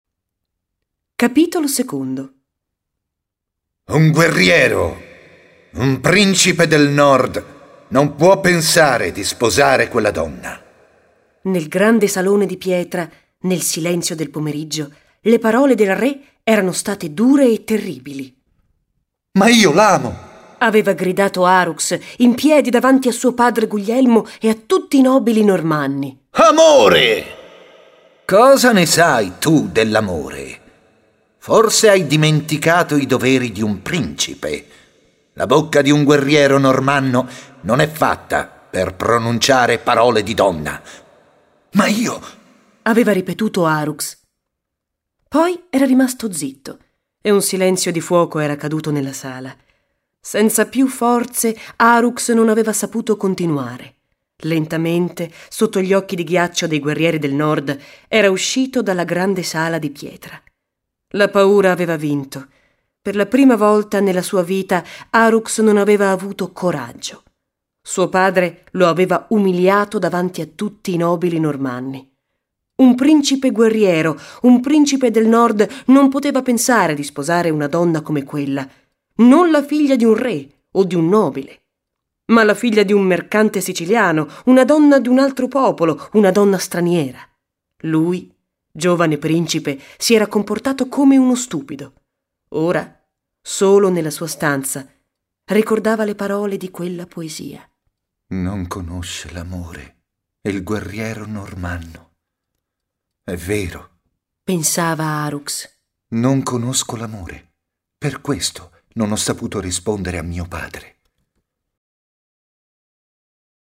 Il volume fa parte della collana Italiano Facile, letture graduate per studenti stranieri con esercizi e versione audio del testo, con voci di attori professionisti ed effetti sonori realistici e coinvolgenti.